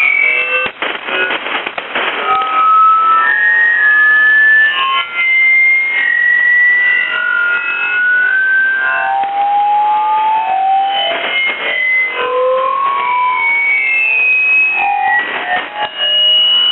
Audio feedback loops on HF
In this example, a remote station sends continuous feedback loops on 6940 kHz USB.
You can see that static crashes, hardly present at my location, are being amplified and retransmitted by the station on 6940 USB!
6940_kHz USB_retransmitting-static.WAV